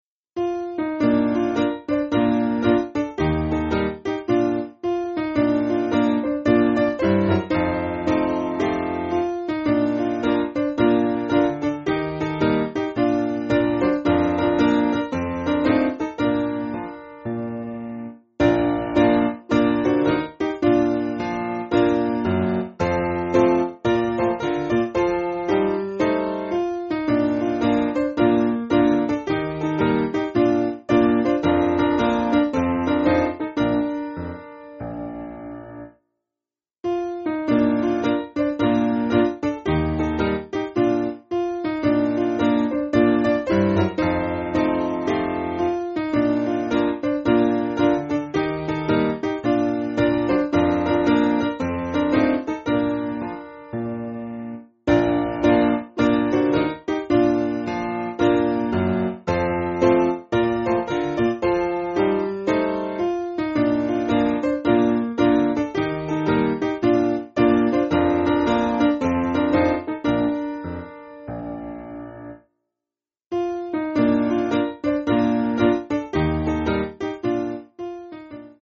Simple Piano
(CM)   3/Bb